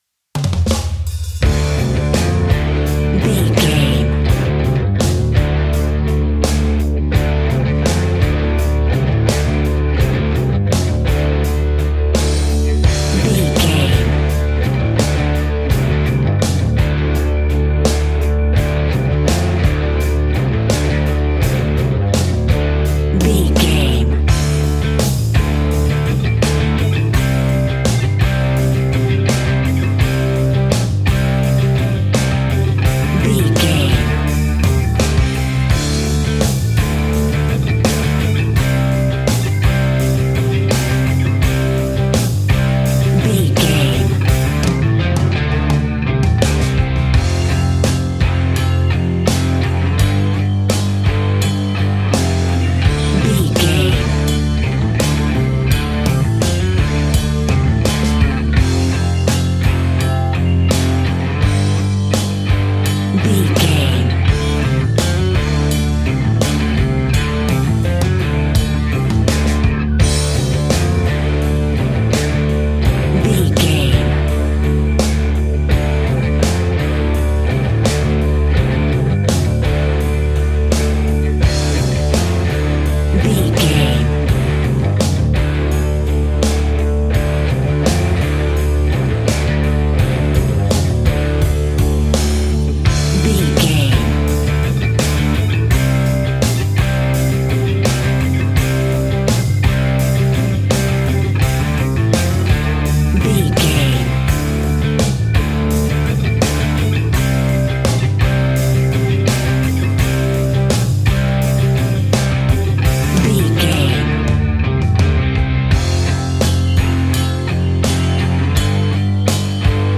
Aeolian/Minor
hard
groovy
powerful
electric guitar
bass guitar
drums
organ